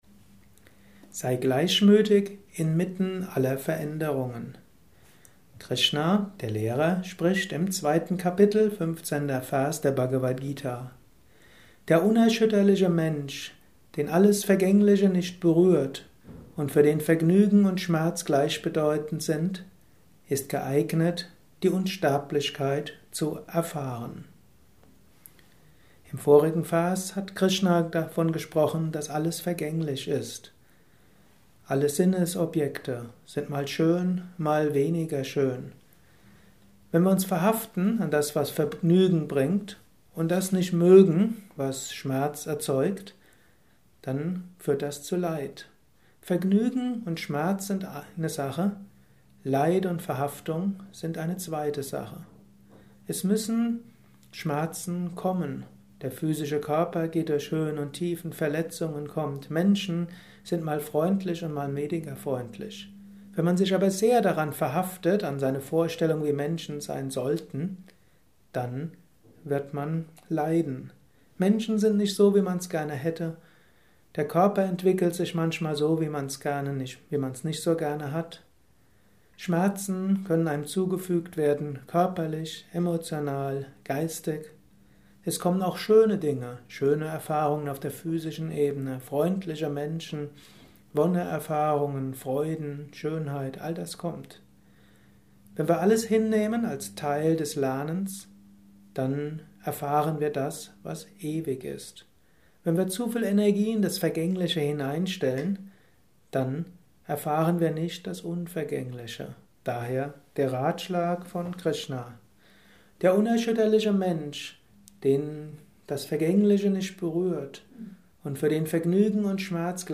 Dies ist ein kurzer Vortrag als Inspiration für